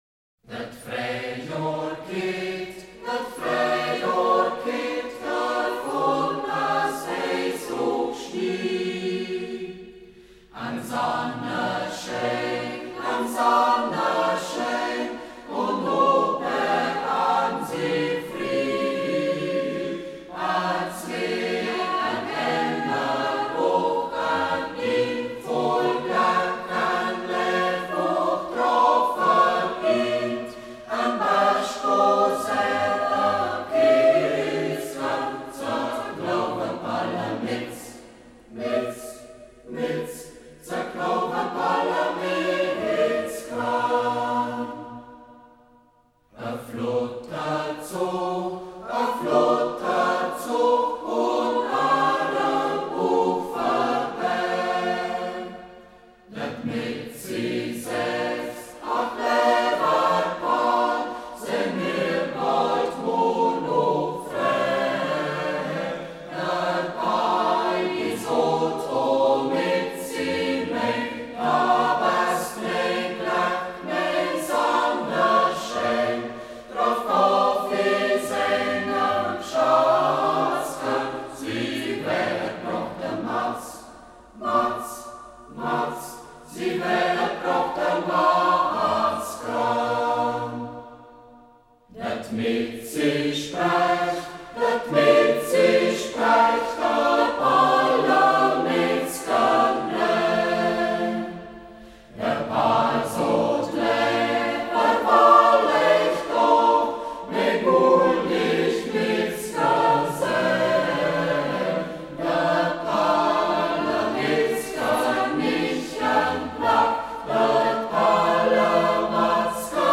Reu�markter Chor • 1998 • Ortsmundart: Reu�markt • 2:24 Minuten Cantores Cibinienses, Brukenthalgymnasium • 1988 • Ortsmundart: Hermannstadt • 1:56 Minuten